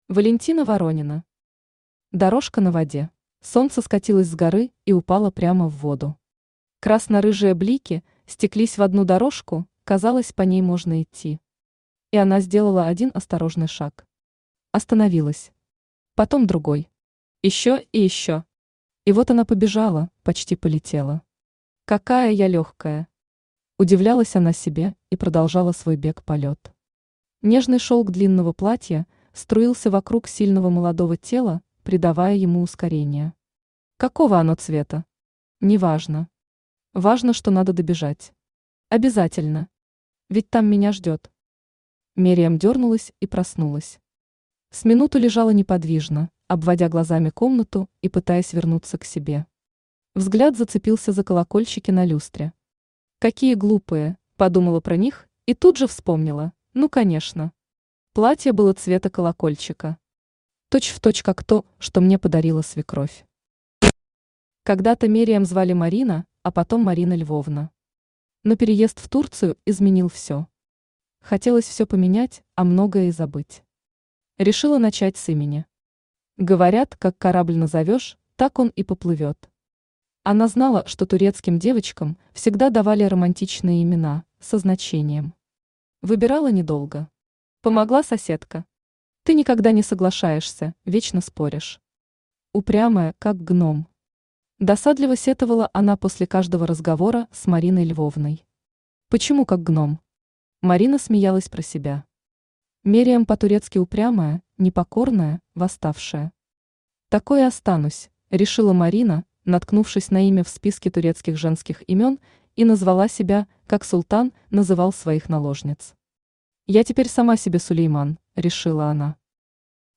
Аудиокнига Дорожка на воде | Библиотека аудиокниг
Aудиокнига Дорожка на воде Автор Валентина Владимировна Воронина Читает аудиокнигу Авточтец ЛитРес.